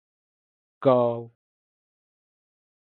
Read more Meaning A Scottish surname from Scottish Gaelic. Pronounced as (IPA) /ɡaʊ/ Etymology From Scottish Gaelic gobha (“smith”).